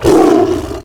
hurt3.ogg